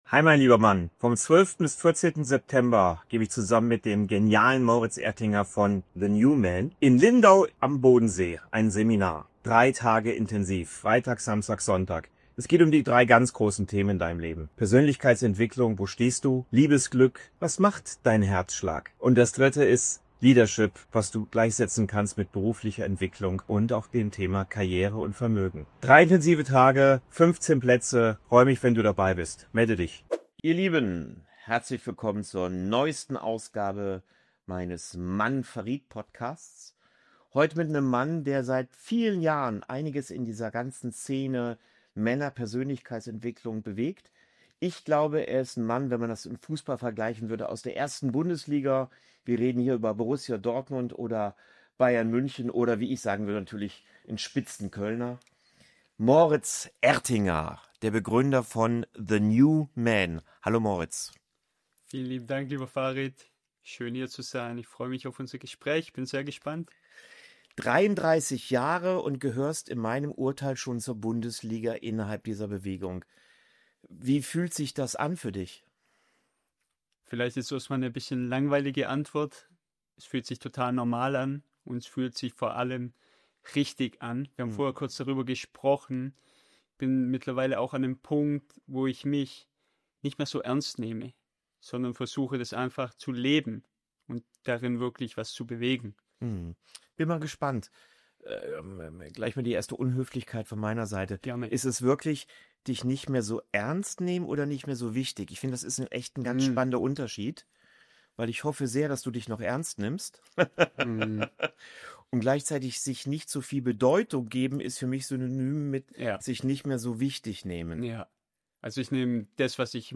Diesmal spreche ich mit einem Mann, den ich richtig spannend finde: